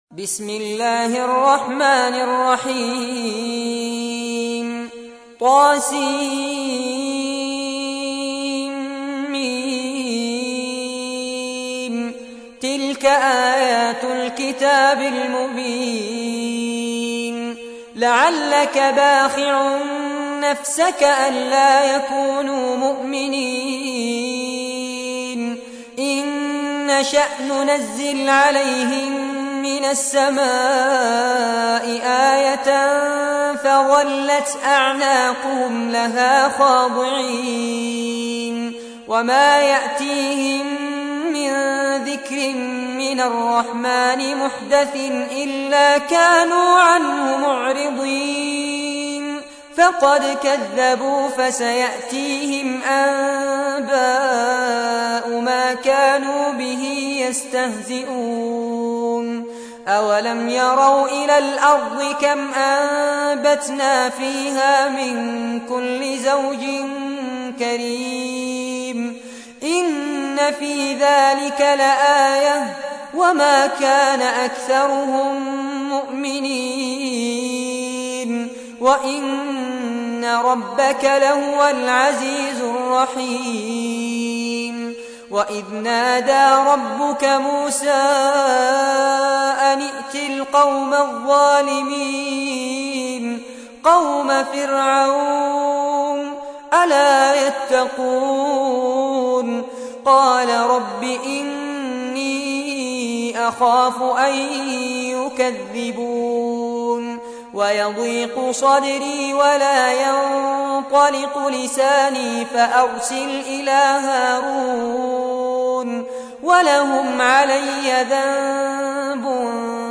تحميل : 26. سورة الشعراء / القارئ فارس عباد / القرآن الكريم / موقع يا حسين